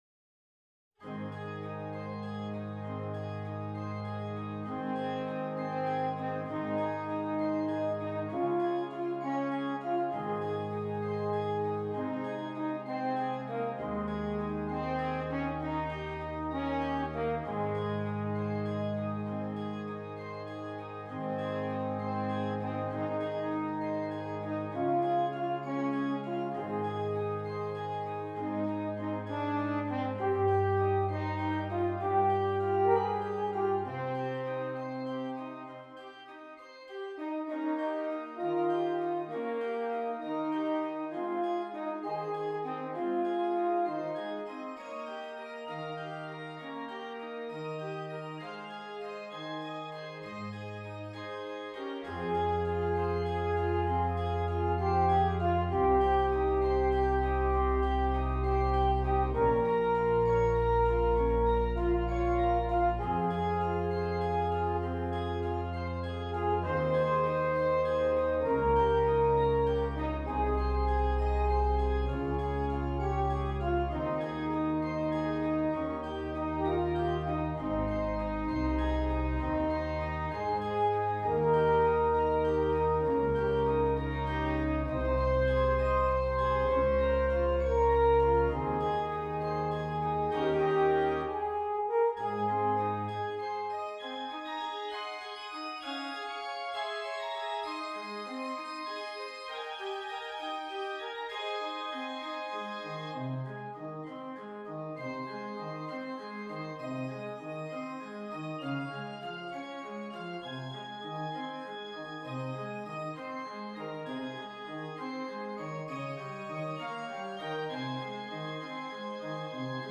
F Horn and Keyboard